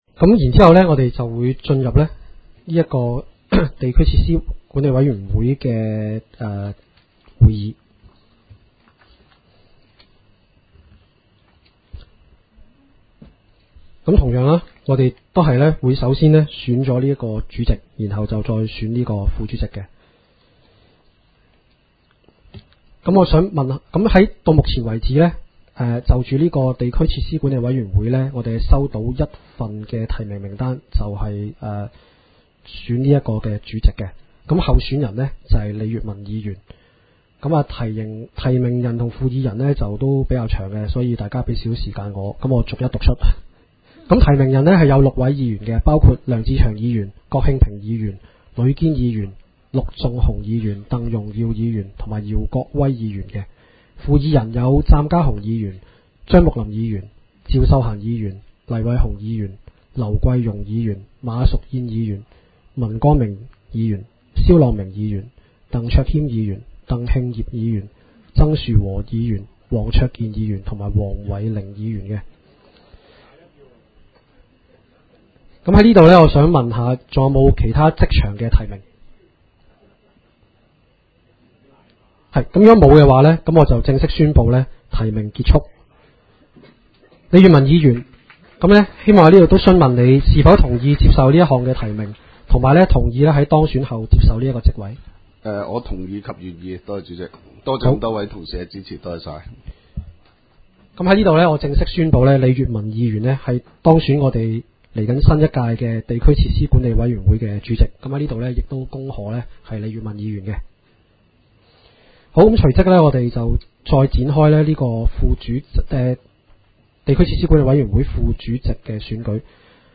委员会会议的录音记录
地点: 元朗桥乐坊2号元朗政府合署十三楼会议厅